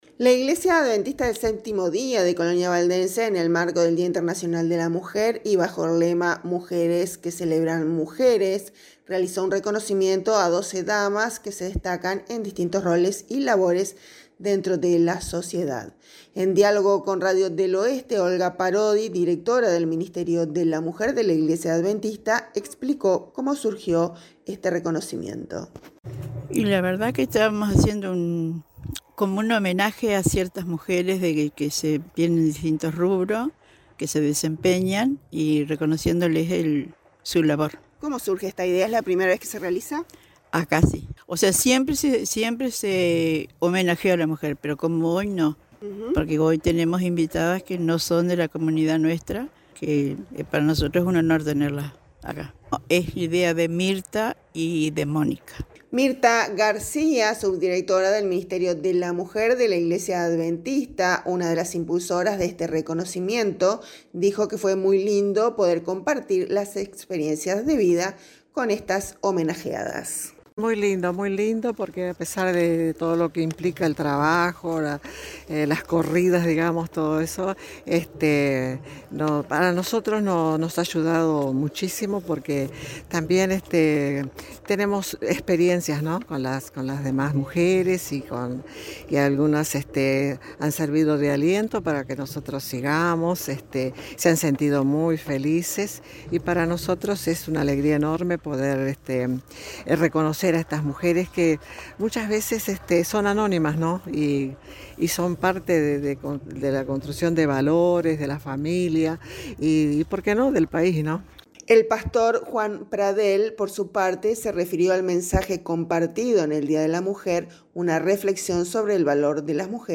En diálogo con Radio del Oeste dijo que este es reconocimiento a la labor de estas mujeres que se desempeñan en distintos rubros, y que desde la Iglesia, si bien no es la primera vez que se homenajea a las mujeres, pero sí lo es sumando a mujeres de la zona que no son integrantes de la comunidad adventista.